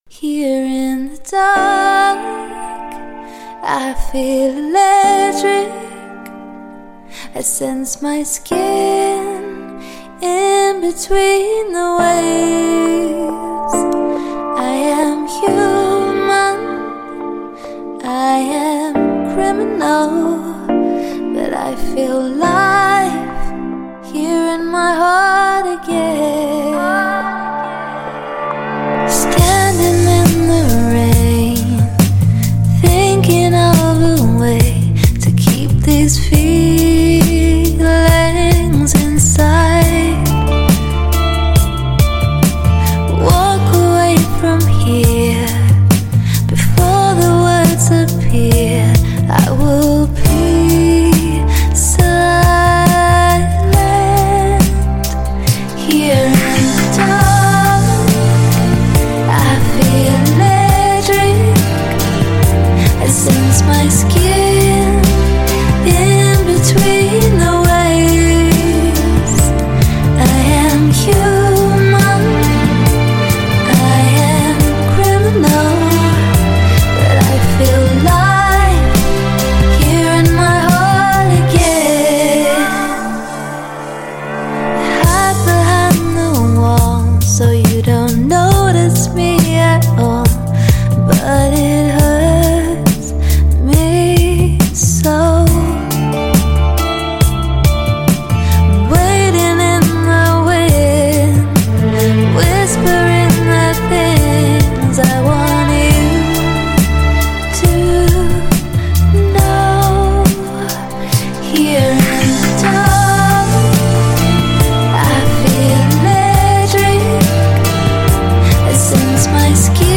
СПОКОЙНАЯ МУЗЫКА с вокалом
спокойная красивая музыка